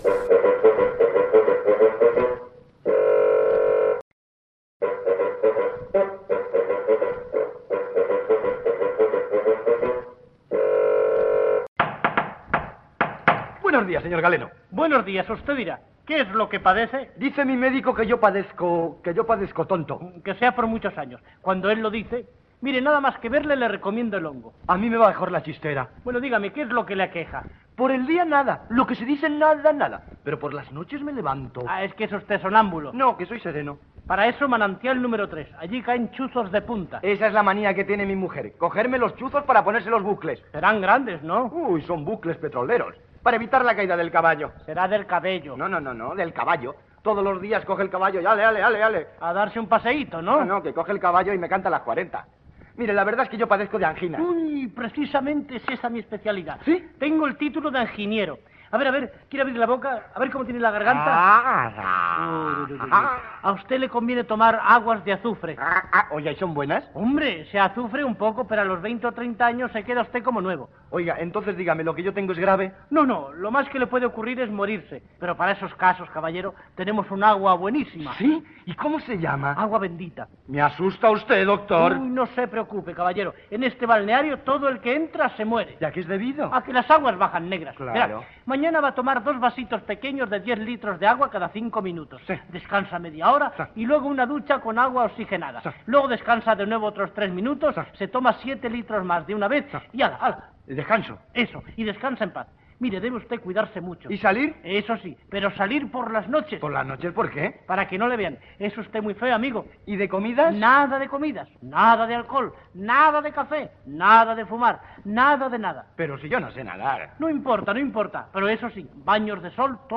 Diàleg humorístic entre un doctor i el seu pacient
Entreteniment